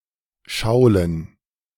Šiauliai is referred to by various names in different languages: Samogitian Šiaulē; Latvian Saule (historic) and Šauļi (modern); Polish Szawle [ˈʂavlɛ]; German Schaulen pronounced [ˈʃaʊ̯lən]
De-Schaulen.ogg.mp3